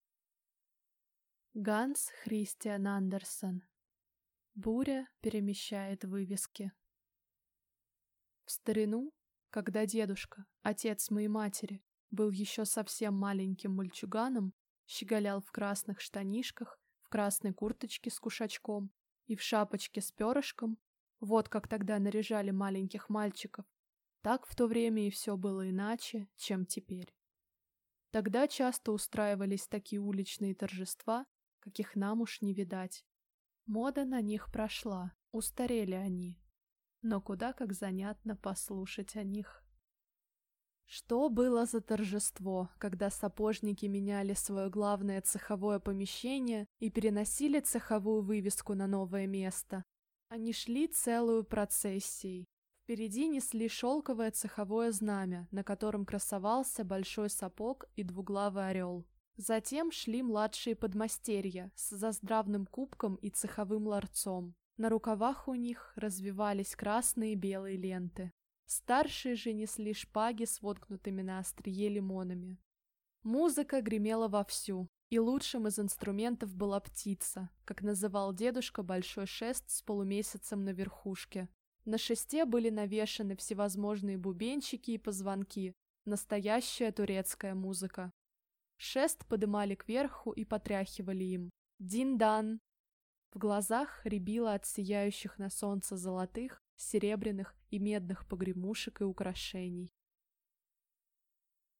Аудиокнига Буря перемещает вывески | Библиотека аудиокниг